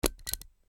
Звуки плоскогубцев
Звук строительного инструмента: плоскогубцы (щелчок)